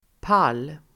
Uttal: [pal:]